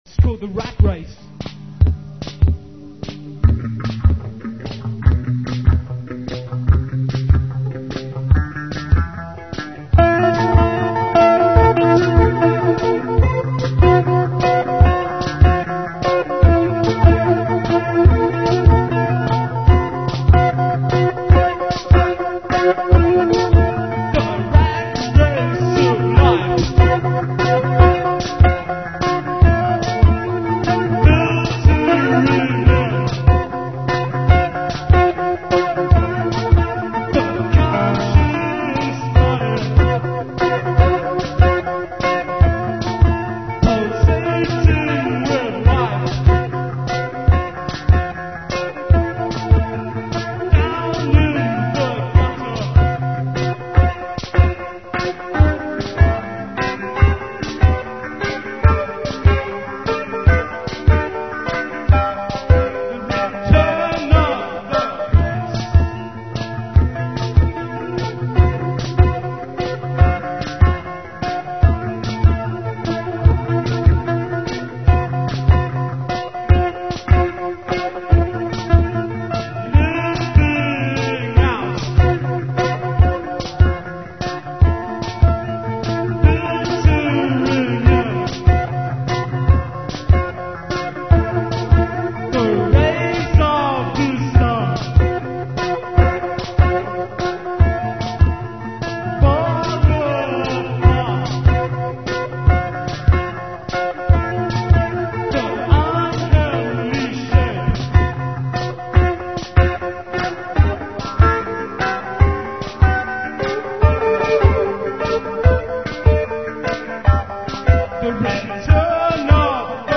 From our widely differing styles would emerge a unique complex hypnotic swirl. It sounded a little menacing at times but it also had a strange allure.
I added the Dr.Rhythm drum-machine to the set-up
- Rat Race, Smile In The Dark, Meaning Of Words, Darkened Ones and A Mirage are taken from the City University's May'82 concert.
Rat Race • 2:57 1982May14 live 520KB 24kHz 24Kb/s